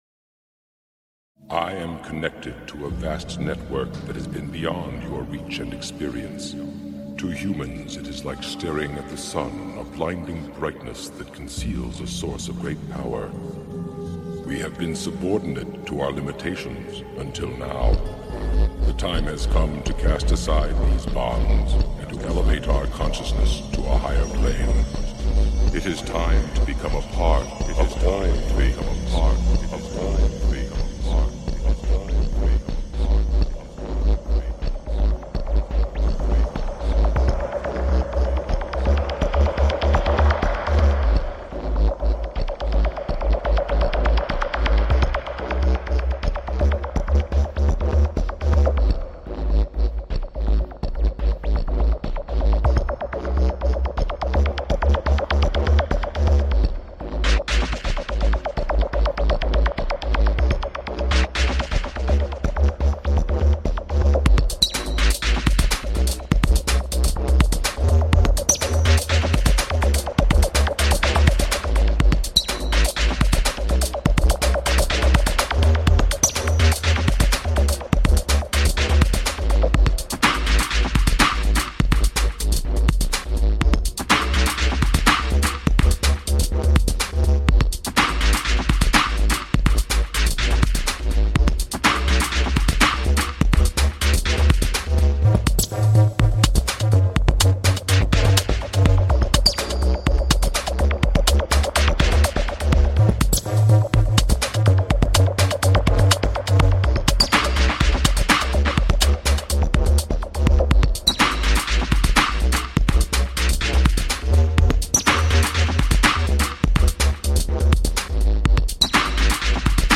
Talk Show Episode, Audio Podcast
This was an abbreviated session due to BBS technical difficulty and featured Mantra Meditation and the latest research from Harvard University concerning the benefits of meditation.